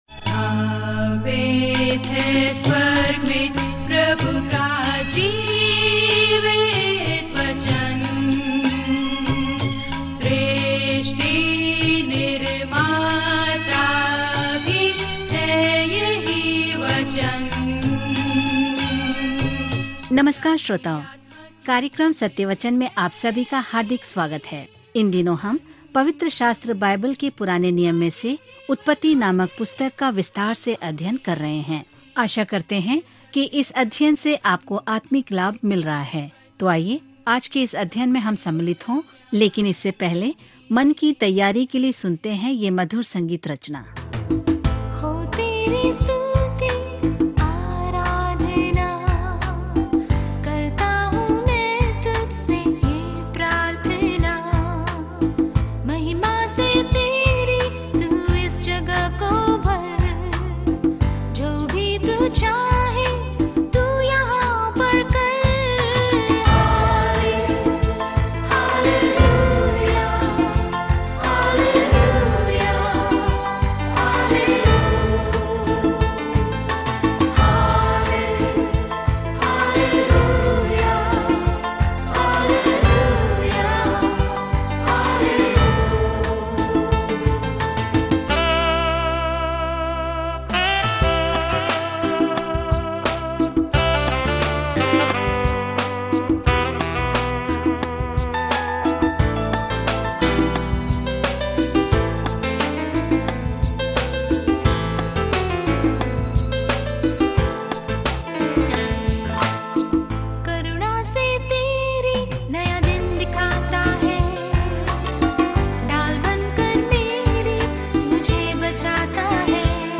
ब्रह्मांड, सूर्य और चंद्रमा, लोग, रिश्ते, पाप- सब कुछ। उत्पत्ति के माध्यम से दैनिक यात्रा करें क्योंकि आप ऑडियो अध्ययन सुनते हैं और उत्पत्ति की पुस्तक में भगवान के वचन से चुनिंदा छंद पढ़ते हैं।